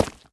Minecraft Version Minecraft Version 1.21.5 Latest Release | Latest Snapshot 1.21.5 / assets / minecraft / sounds / block / tuff_bricks / step6.ogg Compare With Compare With Latest Release | Latest Snapshot
step6.ogg